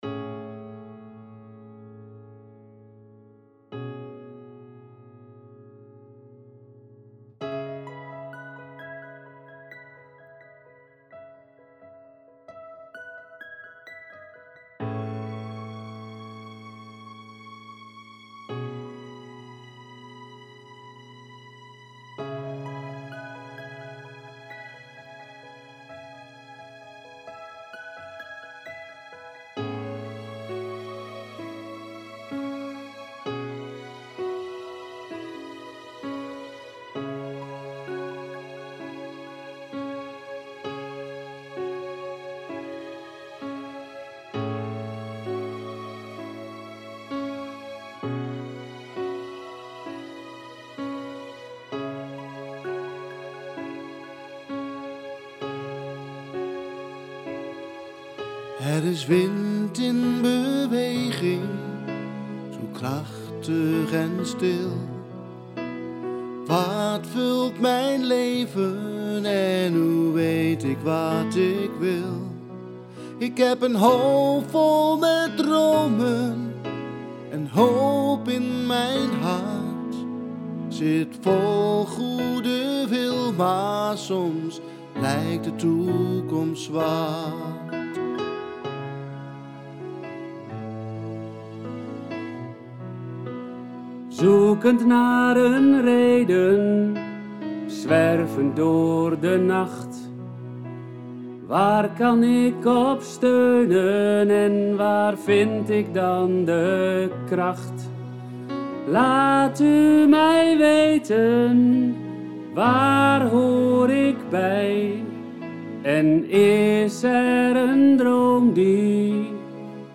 Vocale opname